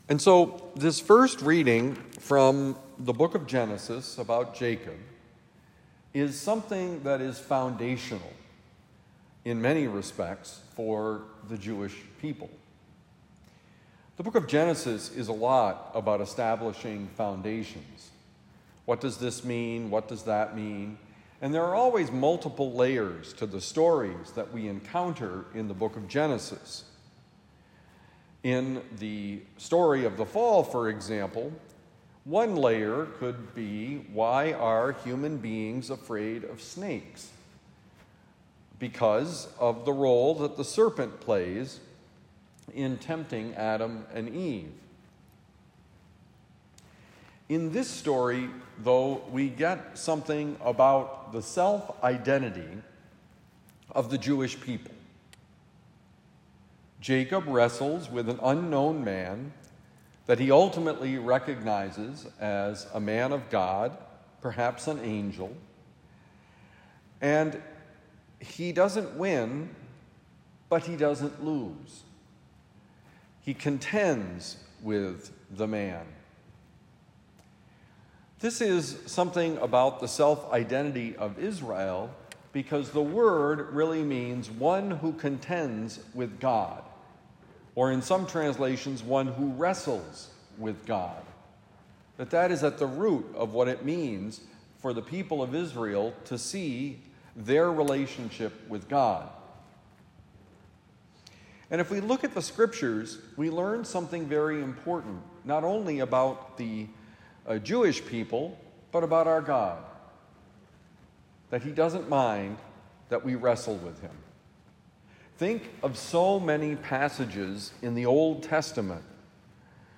Wrestle with God: Homily for Tuesday, July 8, 2025